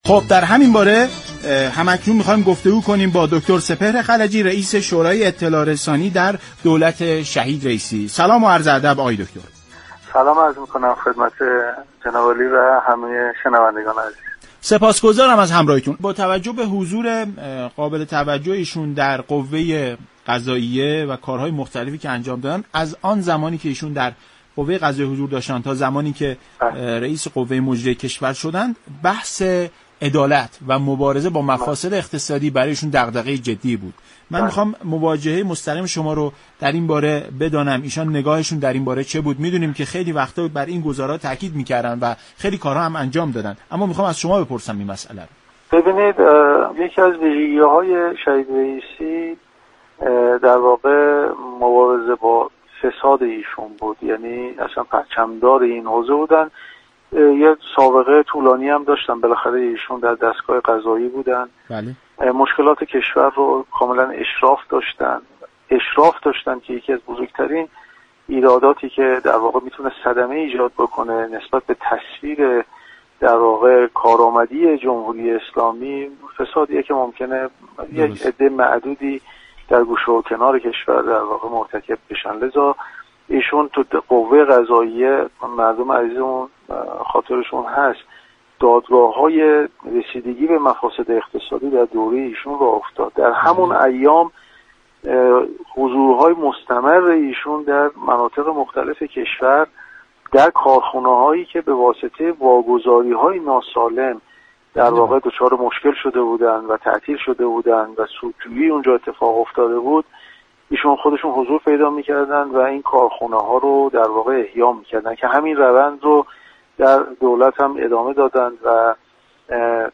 رییس شورای اطلاع رسانی در دولت سیزدهم در برنامه ایران امروز گفت: احیای كارخانه‌هایی كه با واگذاری‌های ناسالم دچار مشكل شده بودند، در زمان تصدی‌گری شهید رییسی در قوه قضاییه انجام شد.